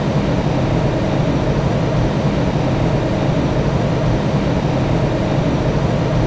IDG-A32X/Sounds/SASA/CFM56/cockpit/cfm-idle2.wav at 41640b0aab405391c8a4d8788da387d27aeb3097
Edit sounds to remove noise and make them loop better
cfm-idle2.wav